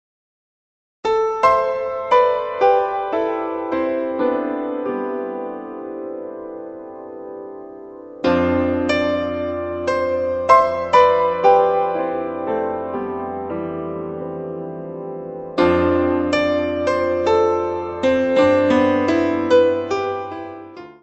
: stereo; 12 cm
Music Category/Genre:  New Musical Tendencies